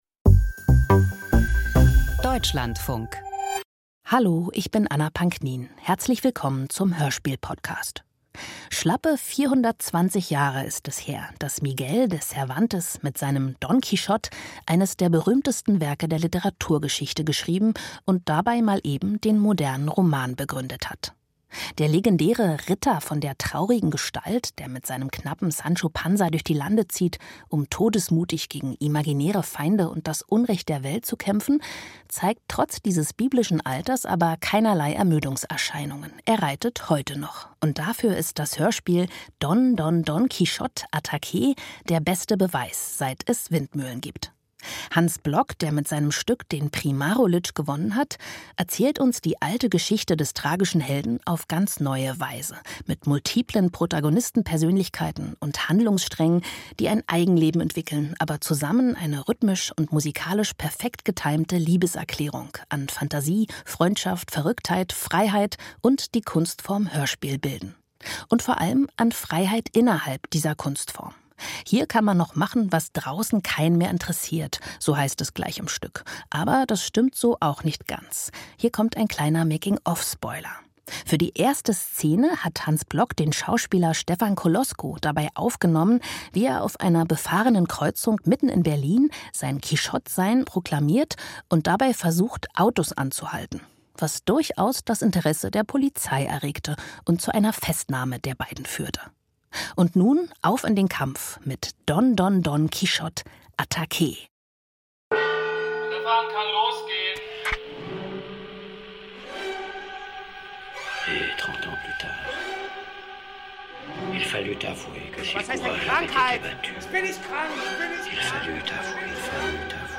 Hörspiel nach Cervantes - Don Don Don Quijote – Attackéee